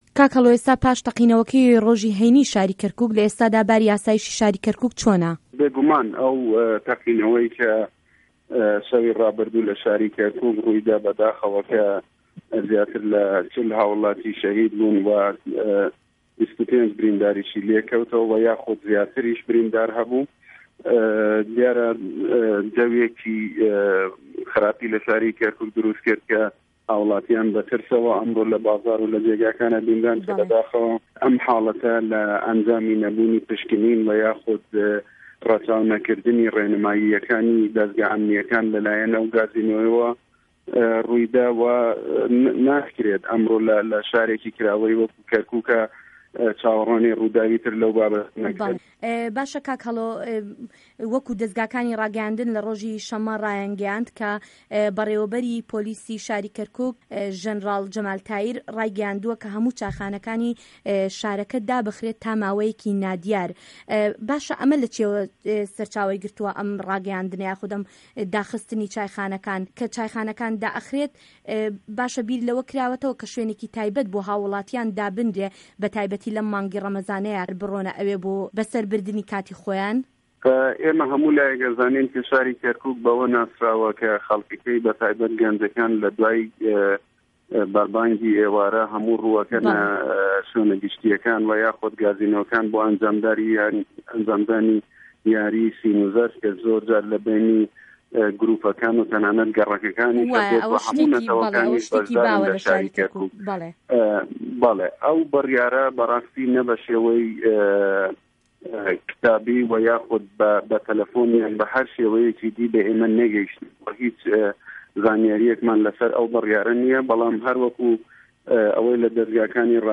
عه‌مید هه‌ڵۆ نه‌جات به‌رپرسی ئاسایشی که‌رکوک له‌ وتووێژێکدا له‌گه‌ڵ به‌شی کوردی ده‌نگی ئه‌مه‌ریکا باس له‌ باری ئاسایشی شاره‌که‌ پاش ئه‌و ڕووداوه‌ ده‌کات و ده‌ڵێت ئه‌و په‌لاماره‌ که‌شێکی ناسه‌قامگیری له‌ شاره‌که‌دا دروست کردووه‌ و هاووڵاتیان به‌ ترسه‌وه‌ ژیان به‌سه‌ر ده‌به‌ن.
وتووێژ له‌گه‌ڵ هه‌ڵۆ نه‌جات